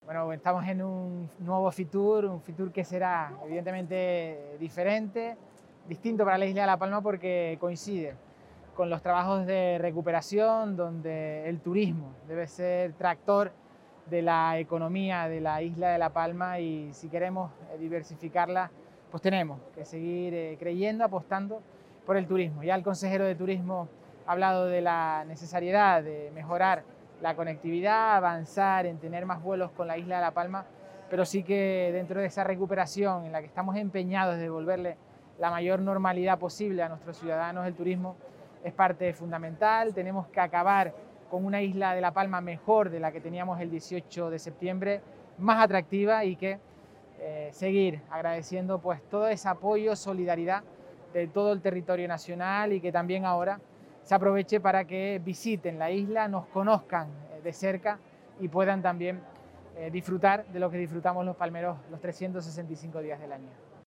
El presidente insular participa en Fitur junto al consejero de Turismo para reforzar la conectividad de la Isla Bonita con los mercados nacional e internacional
Mariano Zapata Fitur 2022.mp3